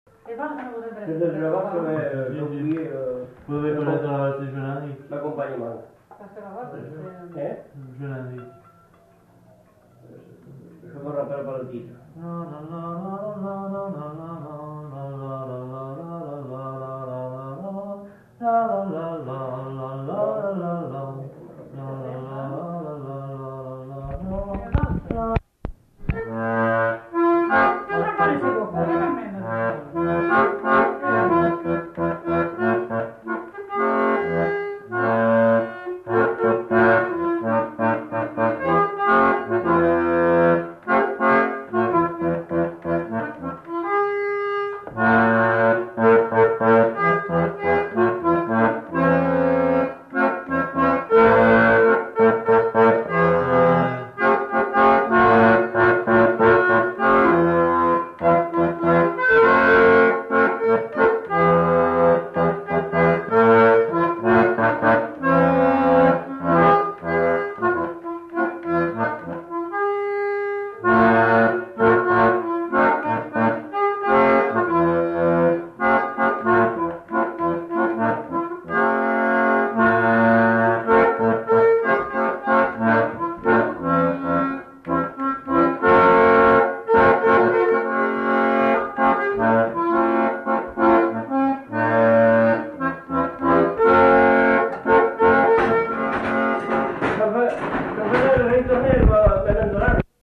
Lieu : Sainte-Maure-de-Peyriac
Genre : morceau instrumental
Instrument de musique : accordéon diatonique
Danse : valse